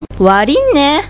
医療人のための群馬弁講座；慣用句
陳謝、感謝など、まるでワイルドカードのような、覚えておくと便利な慣用句．「悪いね」ではなく、速く「わりぃんね！」ないしは「わりぃね！」と発音しよう！